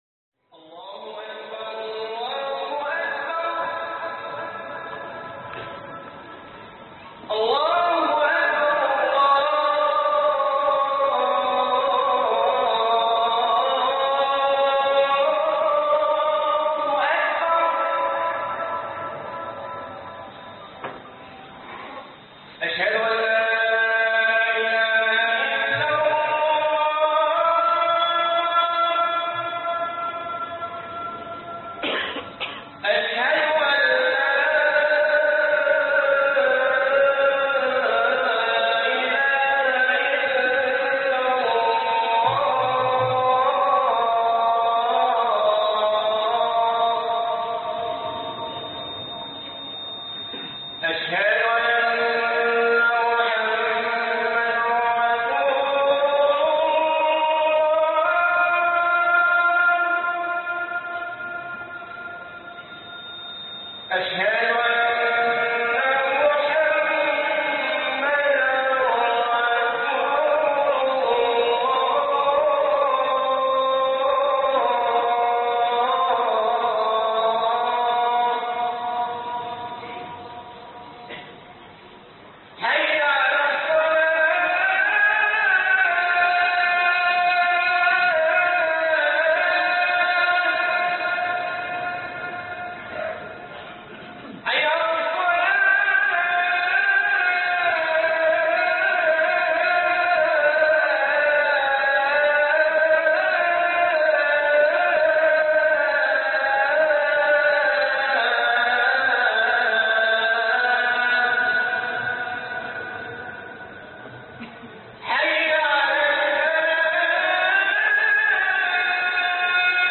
بغداد بوابة المجد - خطب الجمعة